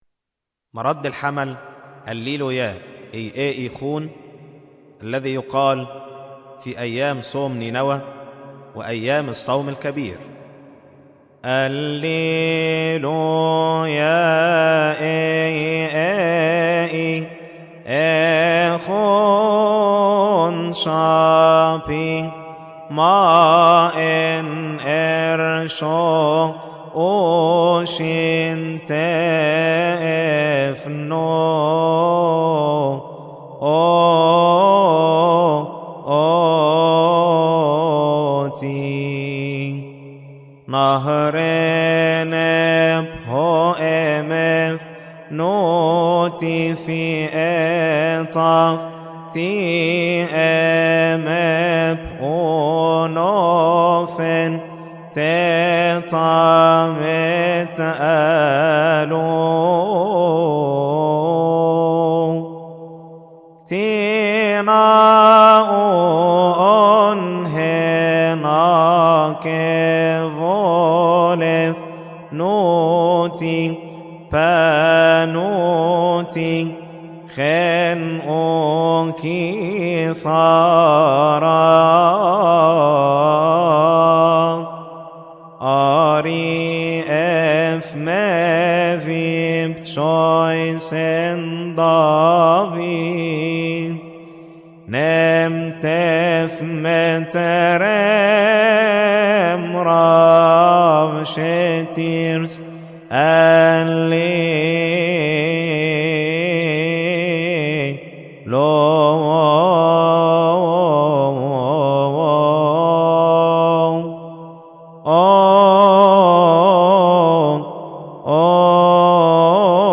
استماع وتحميل لحن الليلويا إي ا ايخون من مناسبة som-kebir